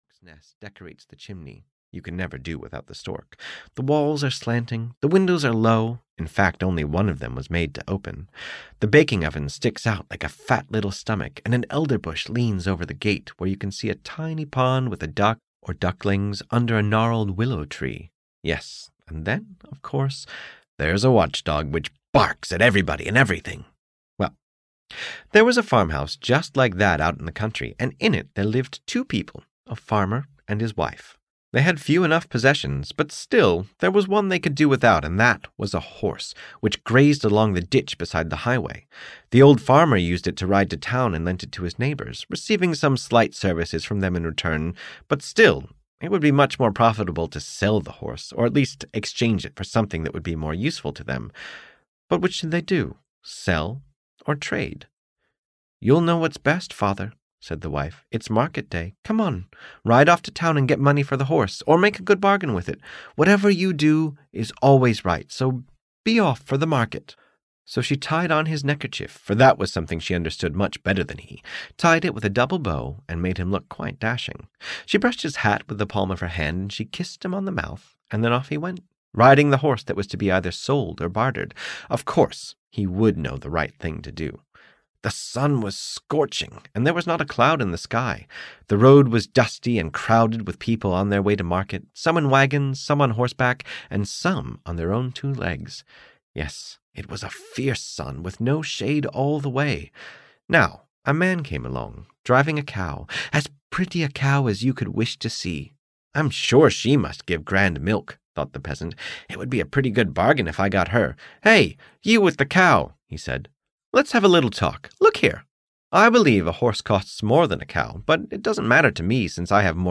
Audio knihaWhat the Old Man Does is Always Right (EN)
Ukázka z knihy